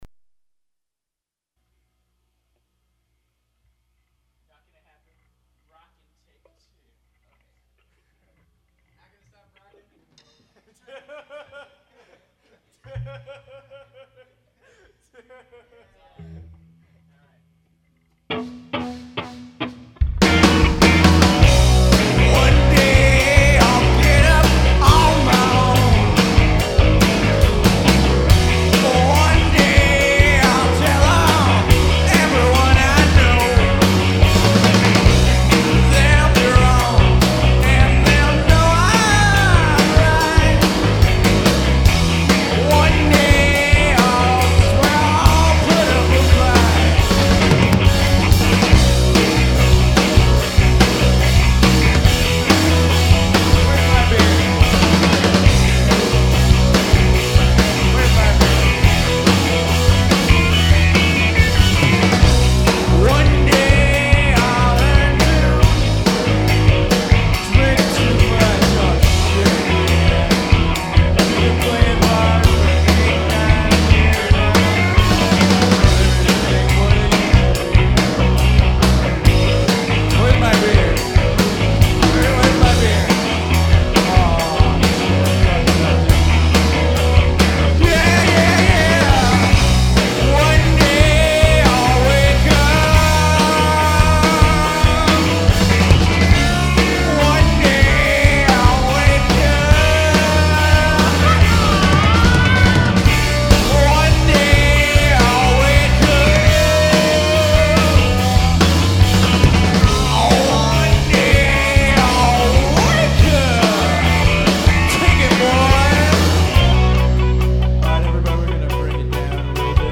the saviors of jangly noodly punk rock.